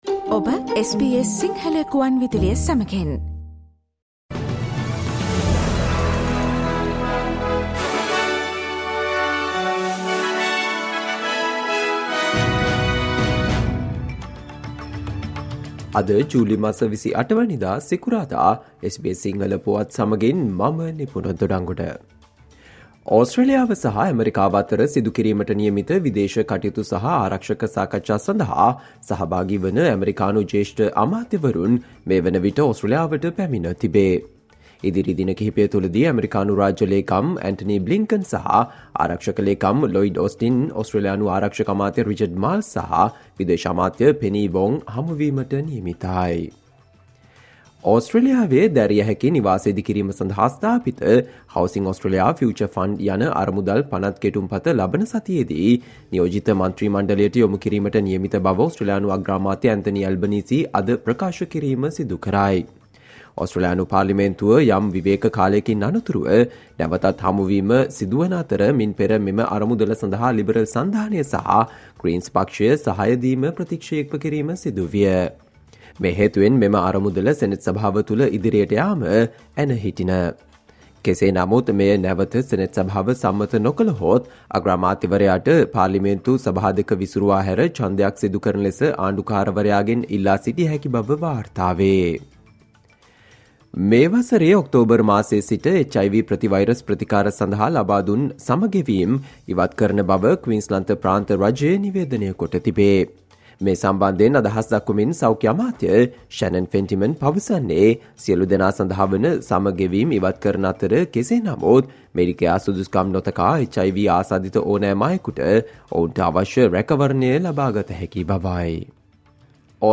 Australia news in Sinhala, foreign and sports news in brief - listen, today - Friday 28 July 2023 SBS Radio News